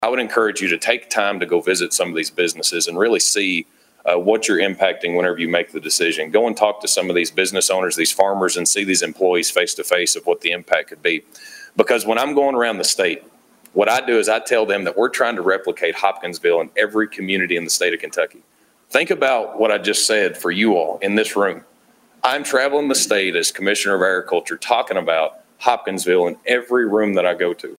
Agriculture leaders and related industries addressed Hopkinsville City Council about the issue Tuesday night.
click to download audioKY Agriculture Commissioner Jonathan Shell joined the group and encouarged the City to look at the impact as well as the quality and commitment of the agriculture community.